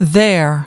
18.Their /ðer/ : của họ, của chúng
their.mp3